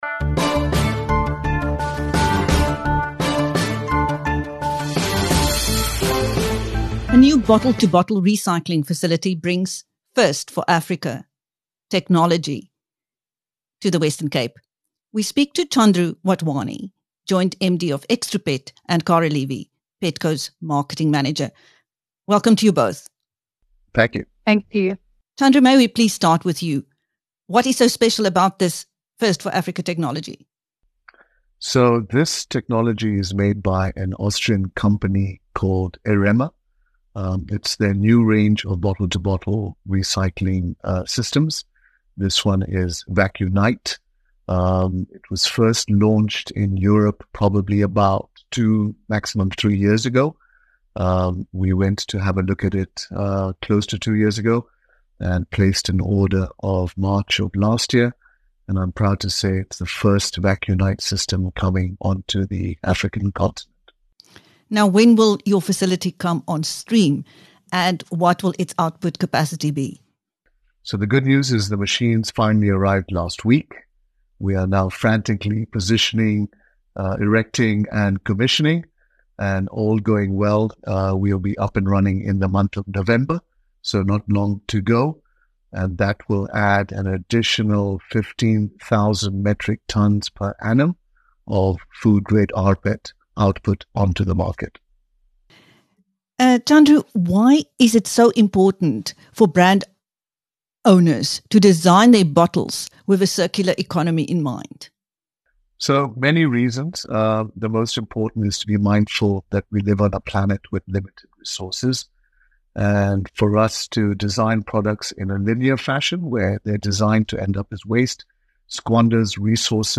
When it comes on stream in 2025, the facility housing the new R300-million project, will bring PET bottle-to-bottle recycling capability to the Western Cape for the first time - and will add an extra 15 000 tonnes per annum of food-grade recycled PET (rPET) output capacity. In this interview with BizNews